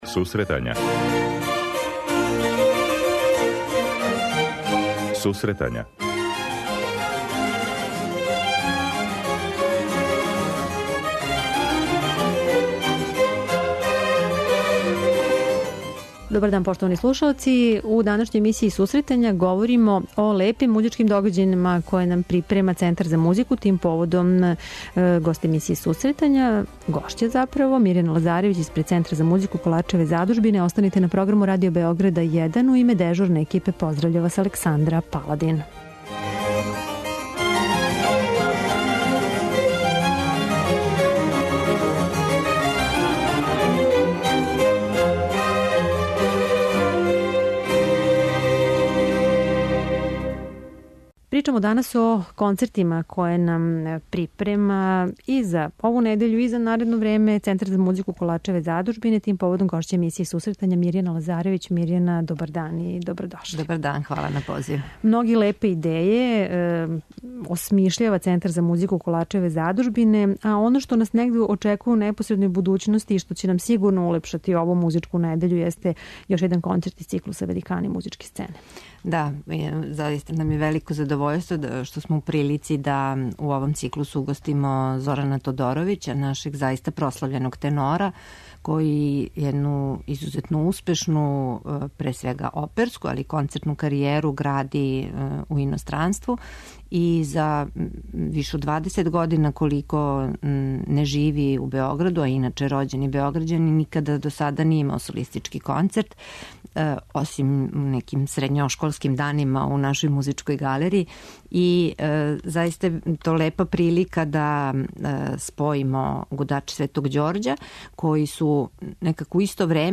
У оквиру циклуса 'Великани музичке сцене' наредног викенда у сали Коларца наступиће чувени тенор светског гласа Зоран Тодоровић, коме је ово први солистички концерт у нашој земљи. Са њим ћемо разговарати током емисије.